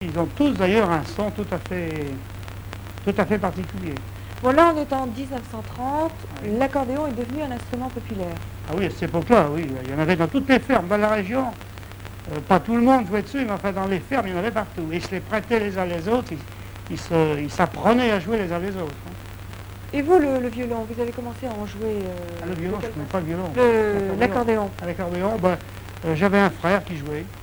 Musique, bal, émission de radio RCF 85
Catégorie Témoignage